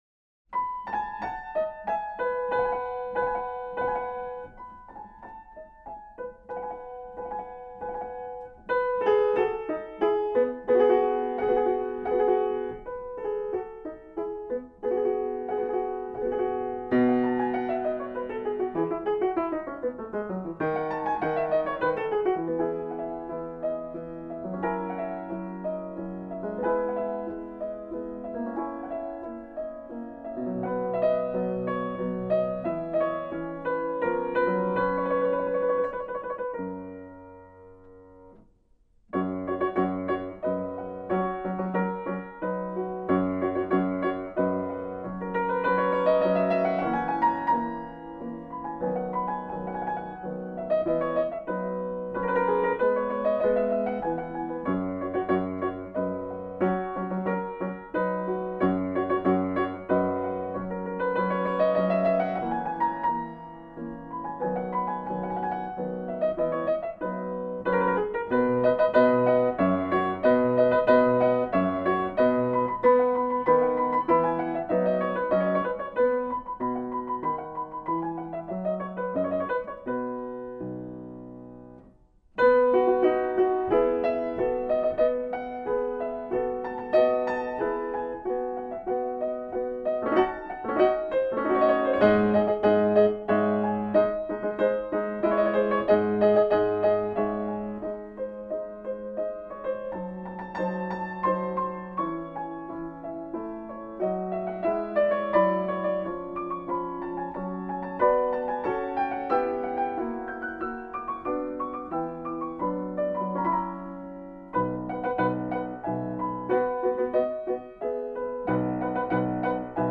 Соната d-moll